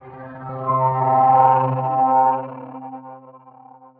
Under Cover (Atmos Noise) 120BPM.wav